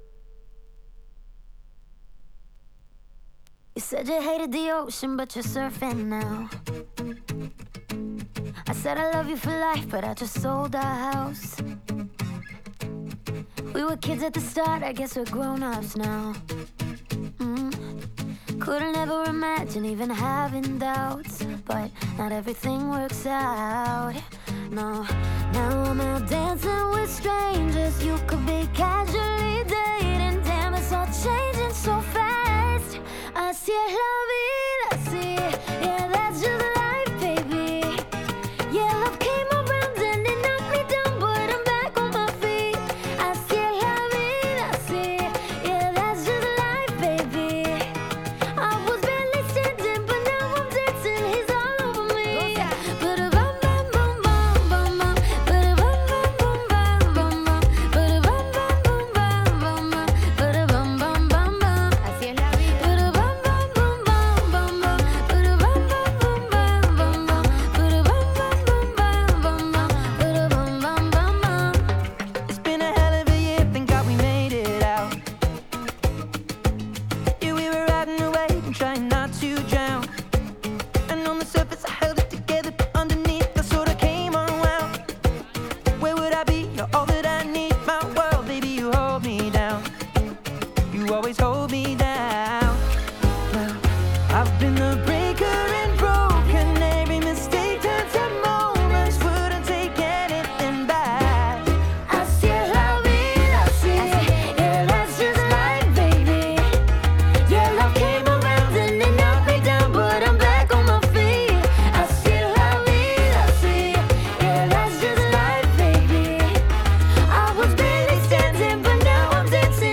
Ze zijn trouwens niet mono, maar stereo.